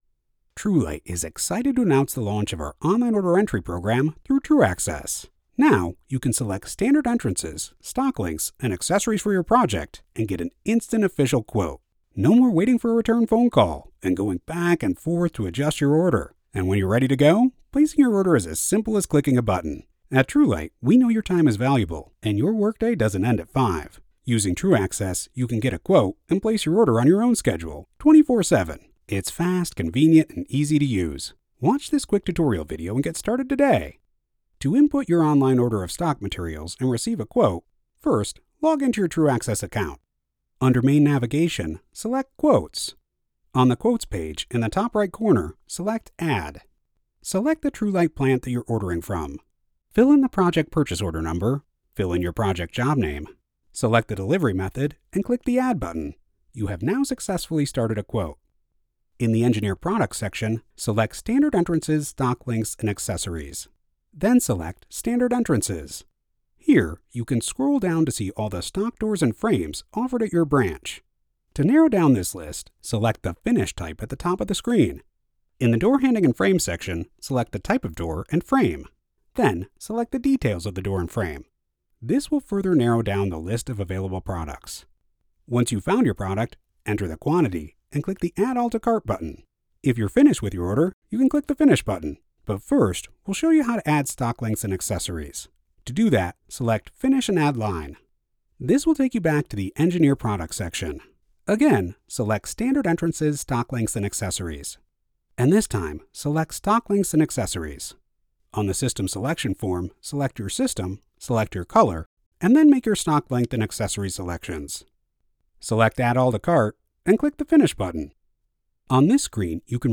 The warm, confident, intelligent voice of the guy next door
Corporate Explainer Video
Midwestern / Neutral
Middle Aged